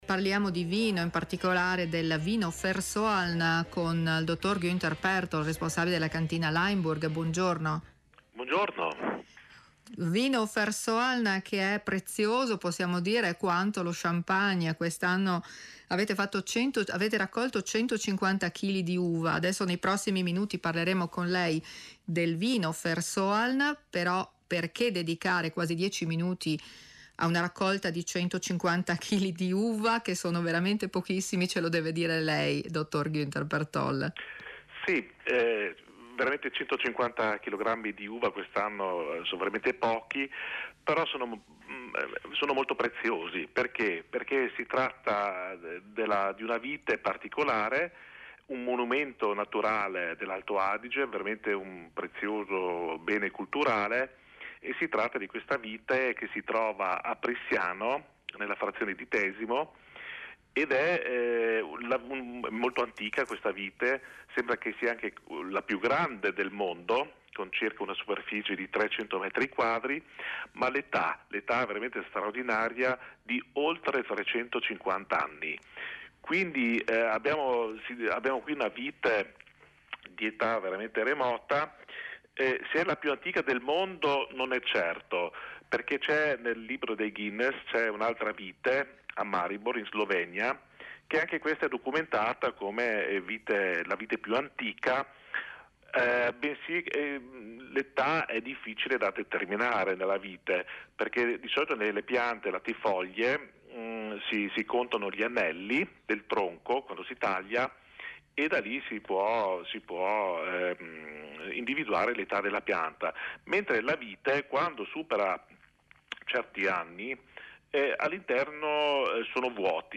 in un'intervista del programma radiofonico "Post it" trasmessa su RAI Alto Adige.